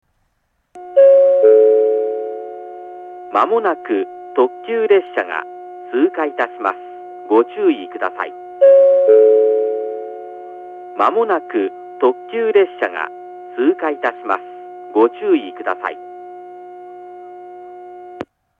この駅の放送はどちらのホームに入線する場合でも上り列車は１番線のスピーカー、下り列車は２番線のスピーカーから放送が流れます。
１番線下り接近予告放送 特急列車通過の放送です。
shinano-tokiwa-1bannsenn-kudari-sekkinn-yokoku.mp3